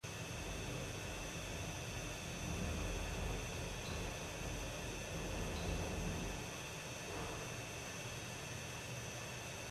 And here is the same clip caught faintly
the second floor.
Then upon listening more, this may actually be a drum beat of some sort.